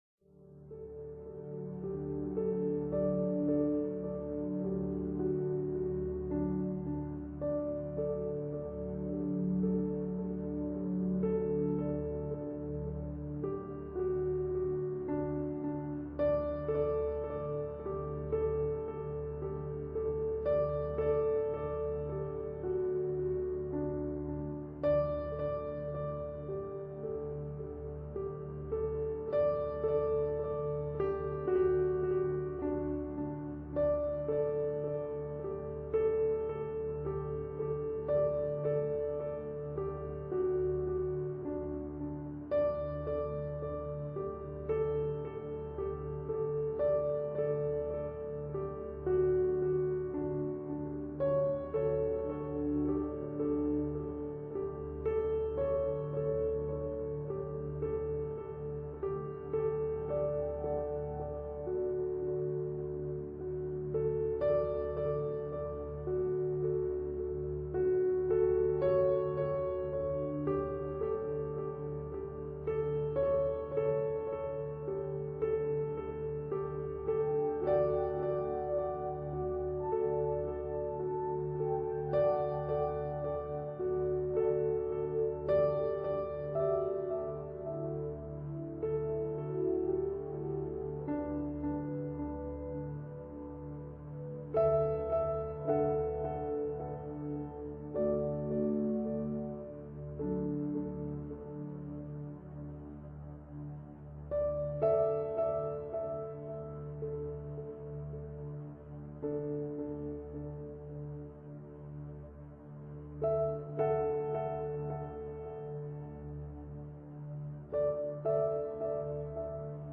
Musique relaxante blue candle
EFFETS SONORES DE LA NATURE audio closed https
Musique-relaxante-blue-candle.mp3